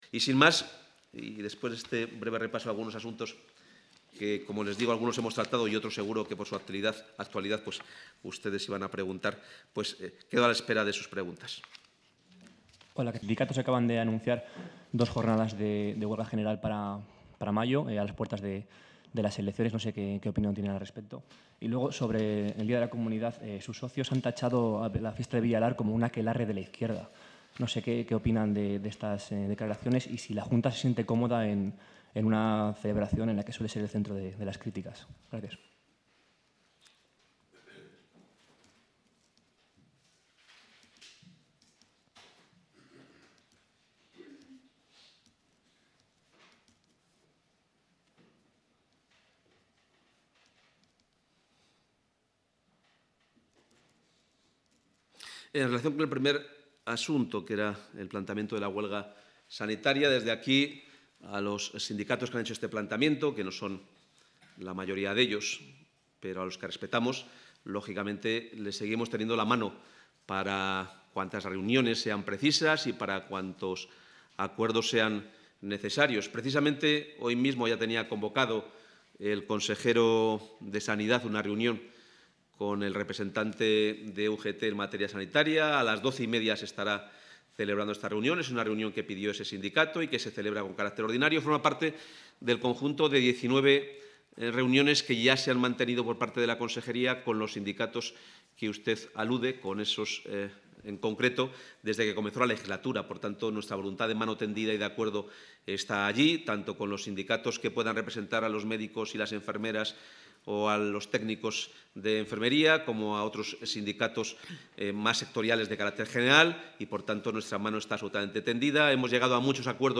Intervención del portavoz de la Junta.